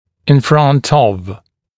[ɪn frʌnt ɔv][ин франт ов]перед